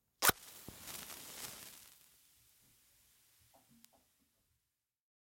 Match Strike " Match Strike 01
描述：击打或点燃一根火柴！在一个安静的地方点燃一根非常靠近麦克风的火柴，以获得良好的声音隔离和细节。一个系列，每根火柴的声音都有点不同，每根火柴都被固定在话筒上，直到它们燃烧殆尽。用森海塞尔MKH8060话筒录入改装的马兰士PMD661.
标签： 匹配 蜡烛 罢工 火柴盒 香烟 弗利
声道立体声